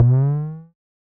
Perc  (10).wav